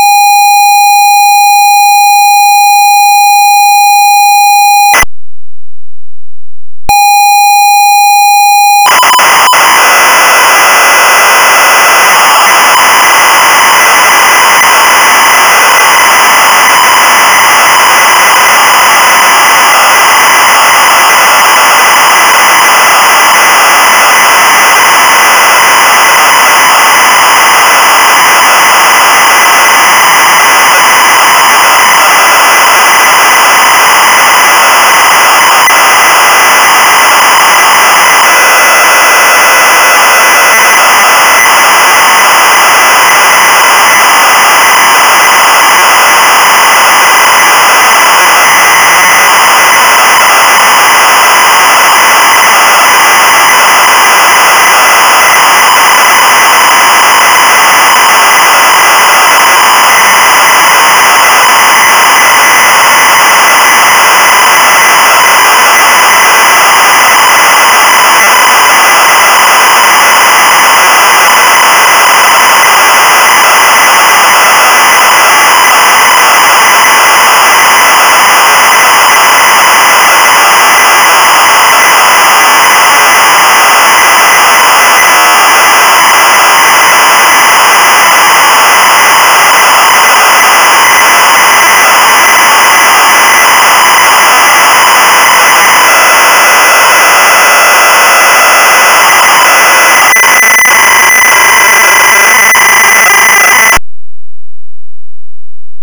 Puteți utiliza unul din fișierele de tip ROM atașate (cu extensia WAV) pentru a încărca sistemul de operare pe un computer CIP-01.
(4.45 MB) - ROM Piatra Neamț BASIC inclus pe caseta demonstrativă